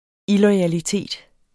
Udtale [ ˈilʌˌjaliˌteˀd ]